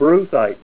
Help on Name Pronunciation: Name Pronunciation: Brucite
Say BRUCITE Help on Synonym: Synonym: ICSD 64722   Nemalite   PDF 7-239